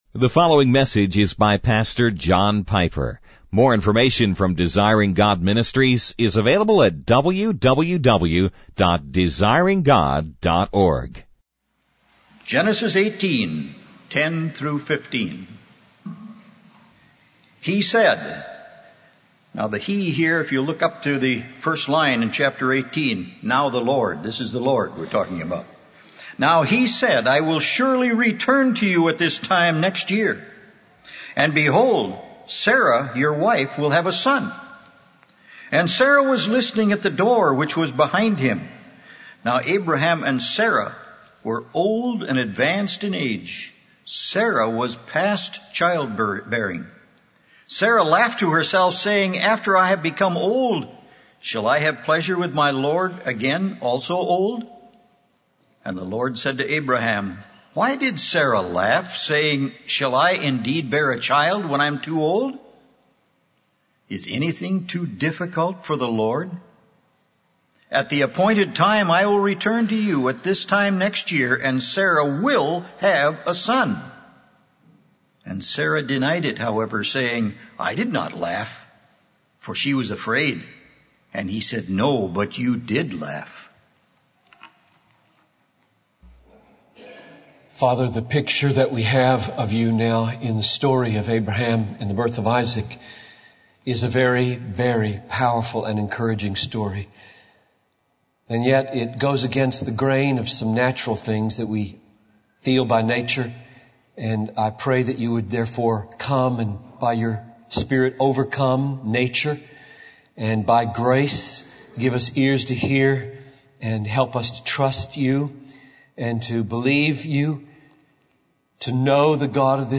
(Education for Exultation) the Isaac Factor by John Piper | SermonIndex